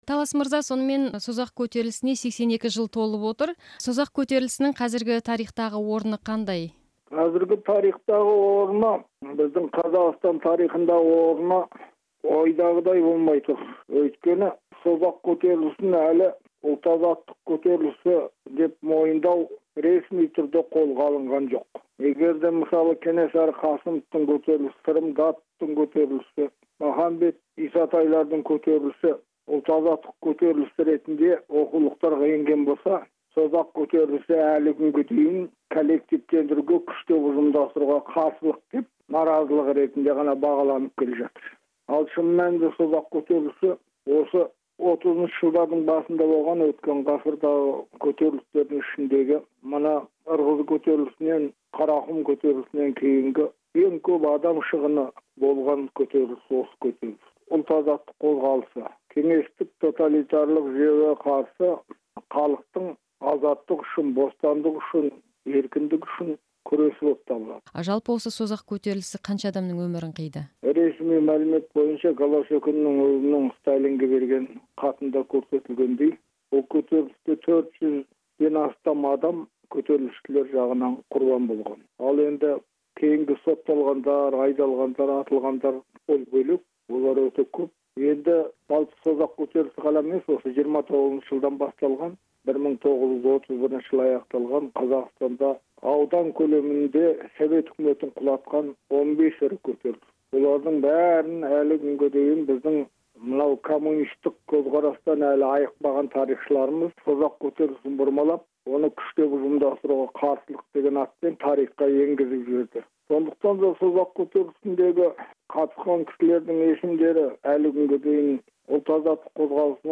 сұқбаты